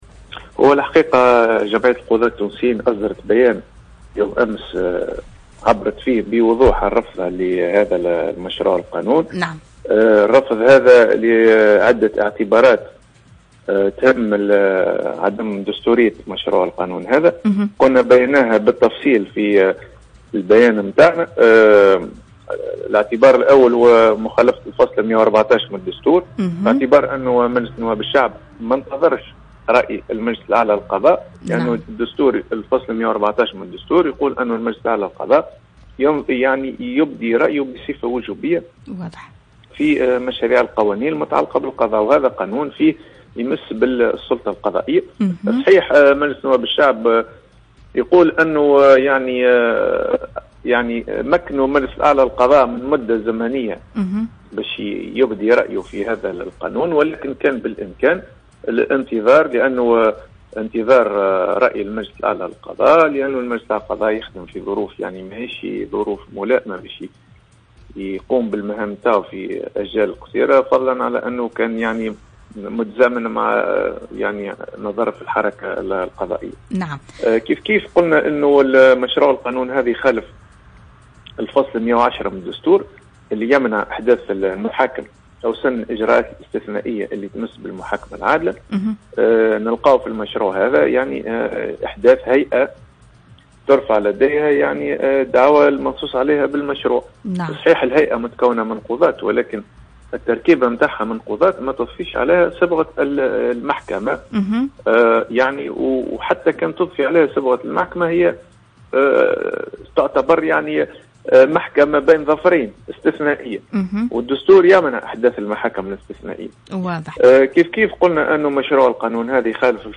قال القاضي حمدي مراد خلال مداخلته في ساعة حرة ان قانون المصالحة مخالف للدستور.
تصريح حمدي مراد